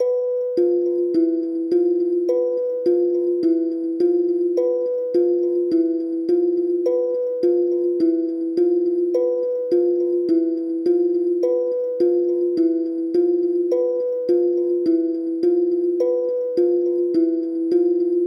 Tag: 105 bpm Rap Loops Piano Loops 3.08 MB wav Key : Unknown